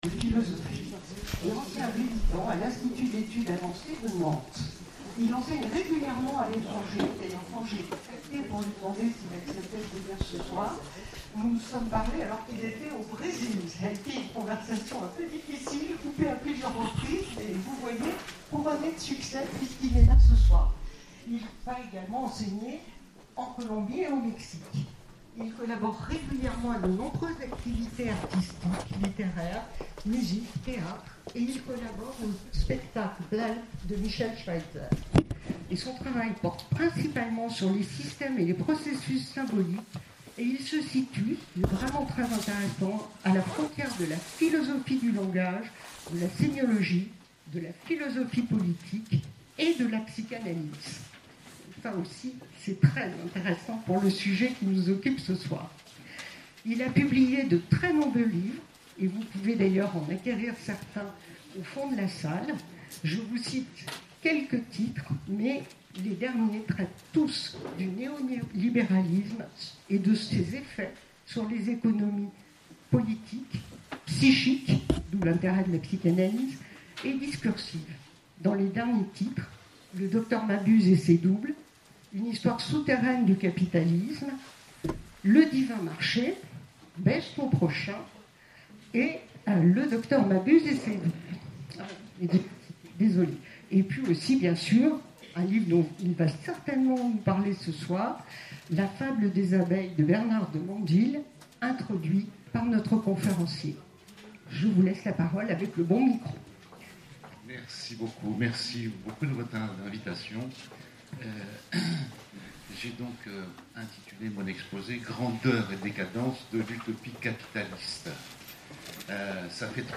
Conférences et cafés-philo, Orléans
CONFÉRENCES